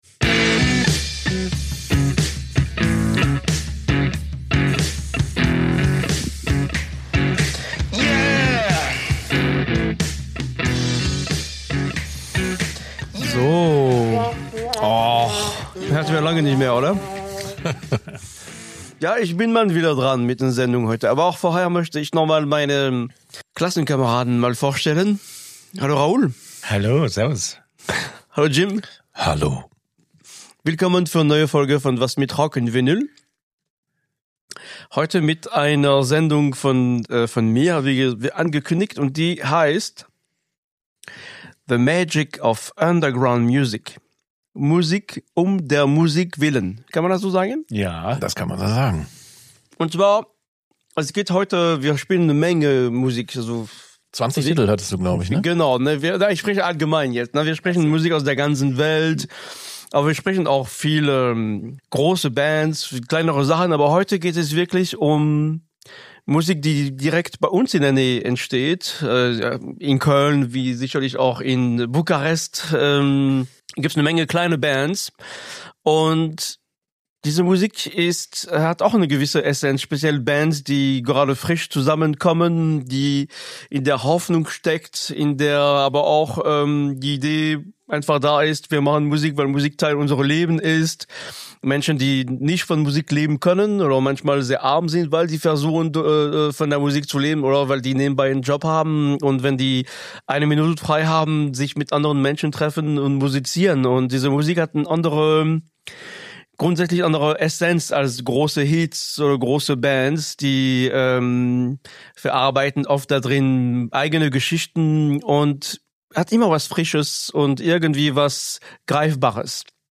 Es brummert und wummert.